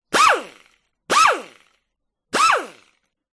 Звуки автосервиса
Электрическая дрель быстро закручивает болт колеса